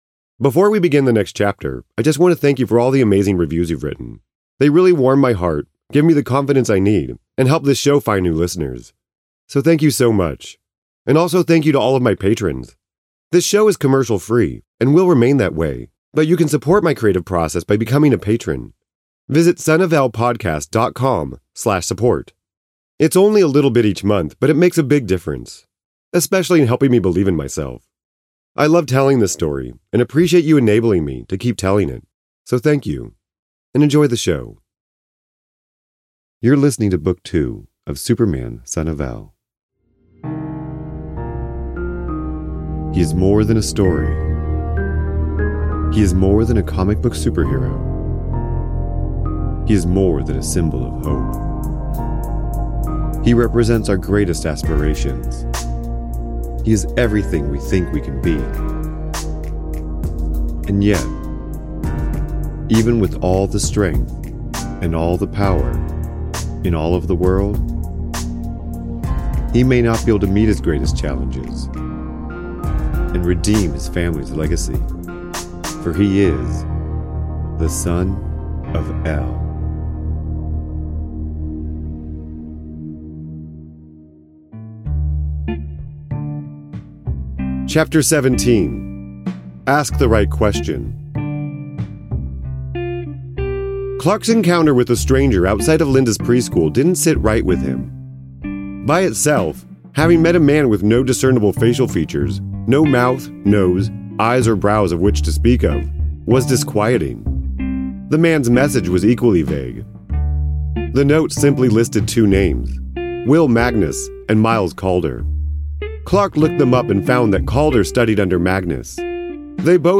Genres: Drama , Fiction , Science Fiction